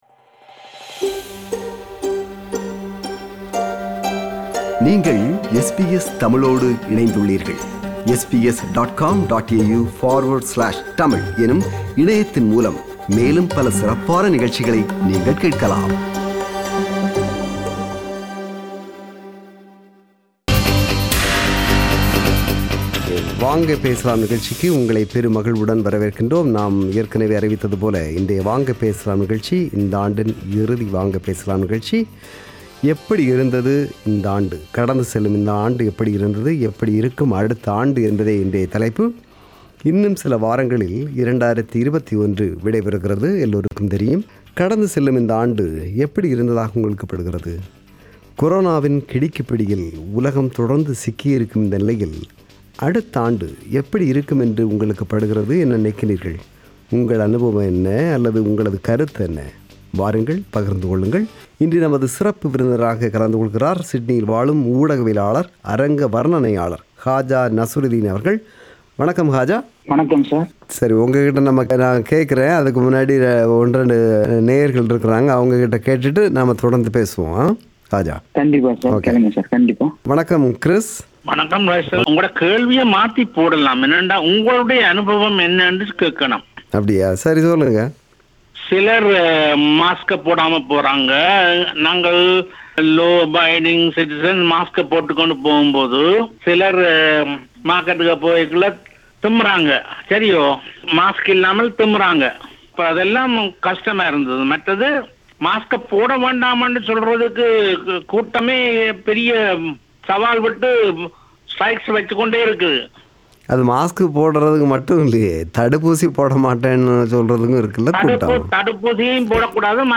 Our listeners shared their views and experiences about the departing year and the year ahead in our monthly talkback program “Vanga Pesalam”.